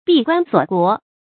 注音：ㄅㄧˋ ㄍㄨㄢ ㄙㄨㄛˇ ㄍㄨㄛˊ
閉關鎖國的讀法